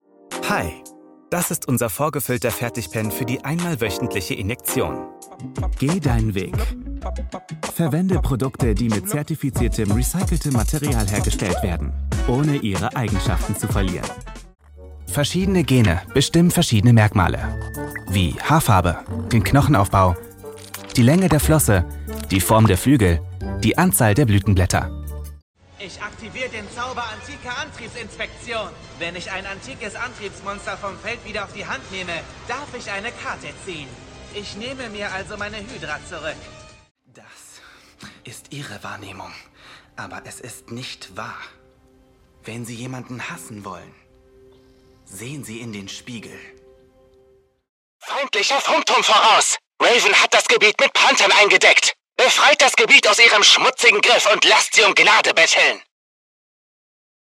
Male
Approachable, Assured, Authoritative, Bright, Bubbly, Character, Confident, Conversational, Cool, Corporate, Energetic, Engaging, Friendly, Funny, Gravitas, Natural, Posh, Reassuring, Sarcastic, Smooth, Soft, Streetwise, Upbeat, Versatile, Wacky, Warm, Witty, Young
Voice reels
Audio equipment: Soundproof recording booth — 38 dB in the midrange and up to 84 dB in the high-frequency range Microphone: Neumann TLM 103 dbx 286s Microphone Preamp & Channel Strip Processor, SessionLink PRO Conferencing wave plugins